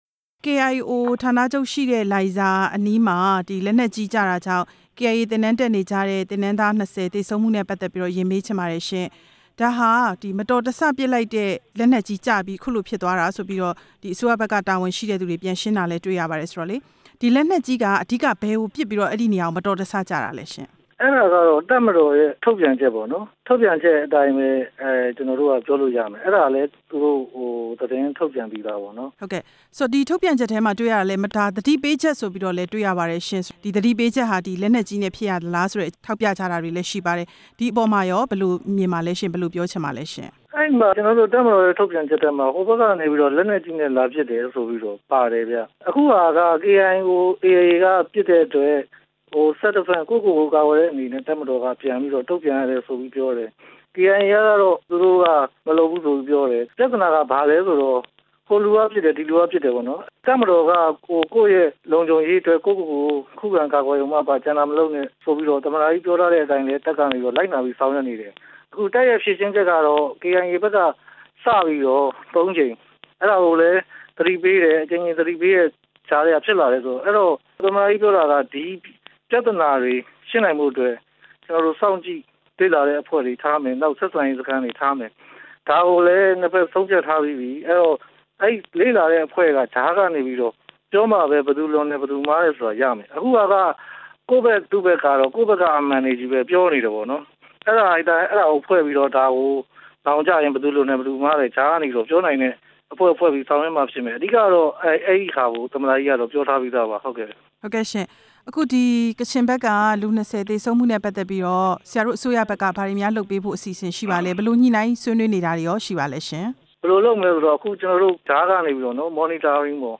သမ္မတရုံး အကြီးတန်းအရာရှိကို မေးမြန်းချက်